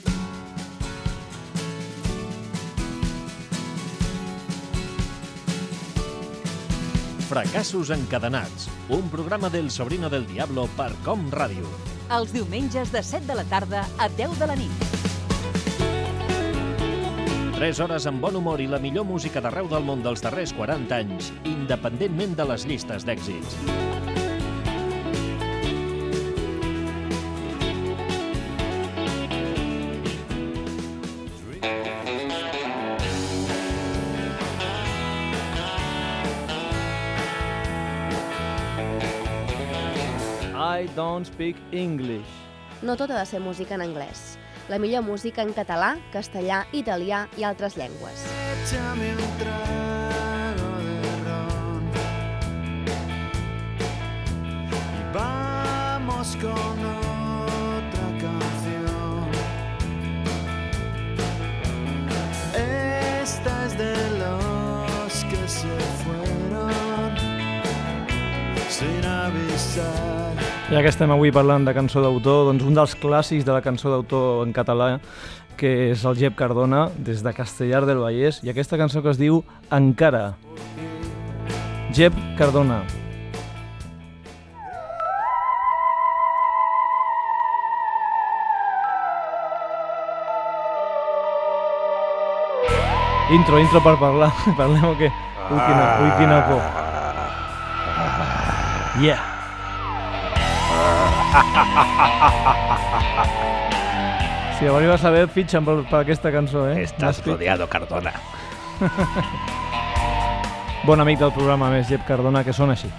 Careta del programa, presentació inicial i tema musical
Musical